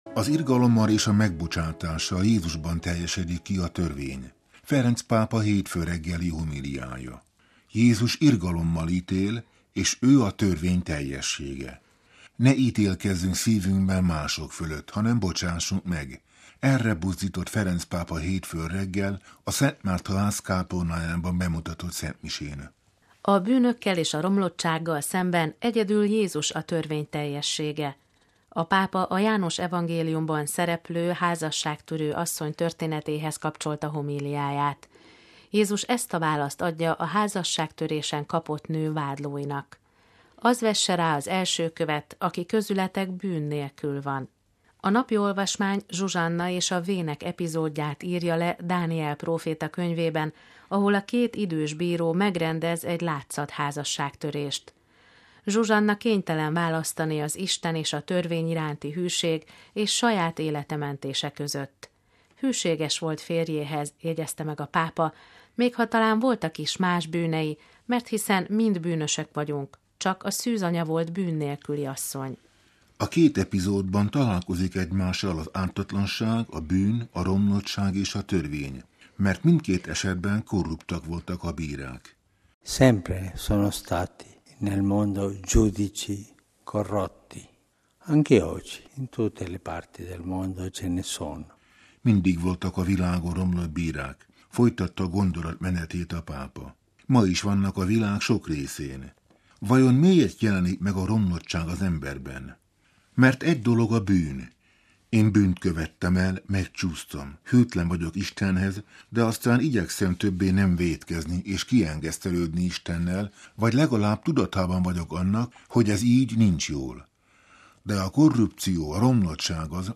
Az irgalommal és a megbocsátással Jézusban teljesedik ki a törvény: a pápa hétfői homíliája
Jézus „irgalommal” ítél, s ő a törvény teljessége. Ne ítélkezzünk szívünkben mások fölött, hanem bocsássunk meg – erre buzdított Ferenc pápa hétfőn reggel, a Szent Márta-ház kápolnájában bemutatott szentmisén.